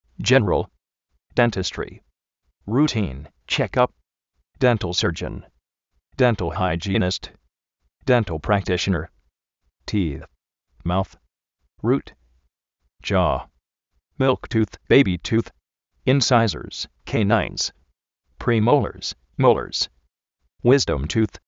déntistri
(rutí:n) chekáp
déntal séryon
tí:z
uísdom tuz